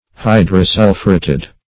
Search Result for " hydrosulphureted" : The Collaborative International Dictionary of English v.0.48: Hydrosulphureted \Hy`dro*sul"phu*ret`ed\, a. (Chem.) Combined with hydrogen sulphide.